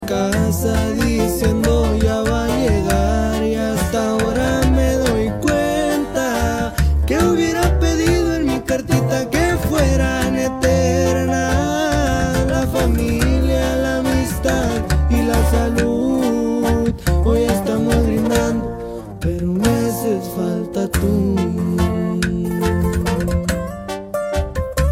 Regional Mexicano